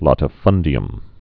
(lătə-fŭndē-əm)